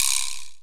Percs
NB - CARTI PERC.wav